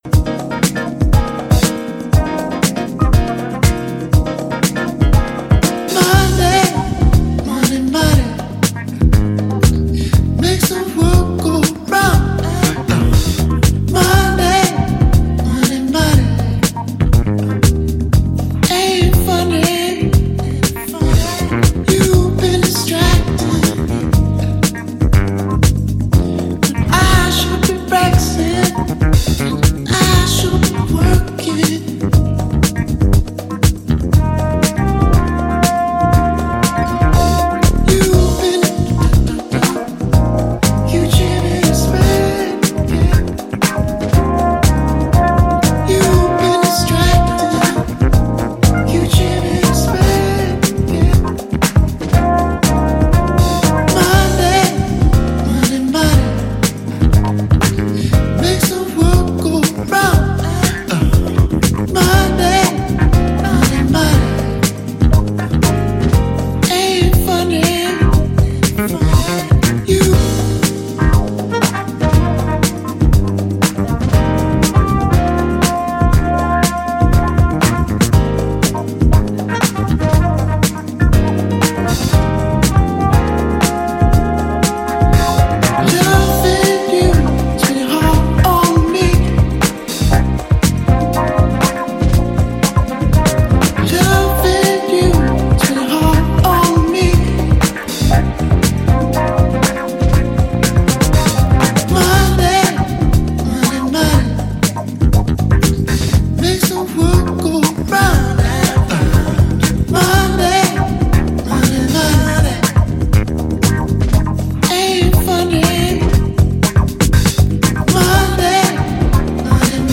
Pure dancefloor joy is guaranteed with this release.
very organic sounding, warm, soul-driven jazzy house songs